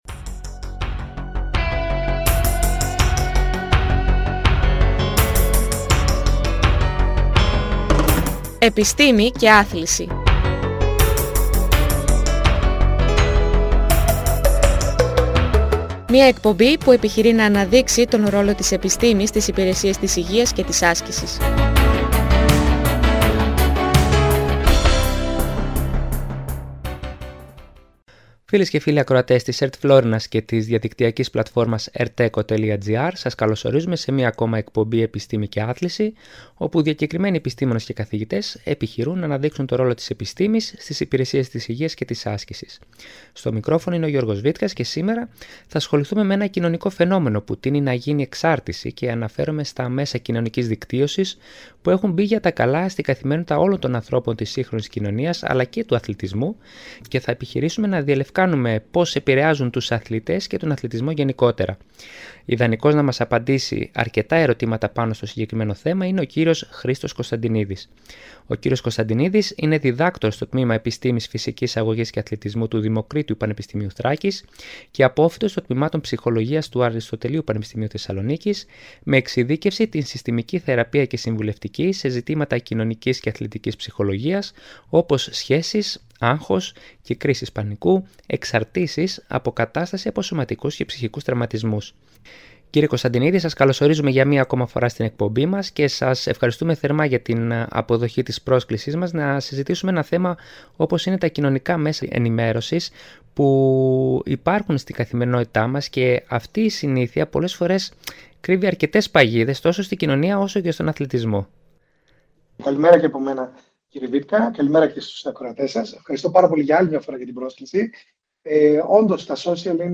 «Επιστήμη και Άθληση» Μια εκπαιδευτική εκπομπή όπου διακεκριμένοι καθηγητές και επιστήμονες, αναδεικνύουν τον ρόλο της επιστήμης στις υπηρεσίες της υγείας και της άσκησης.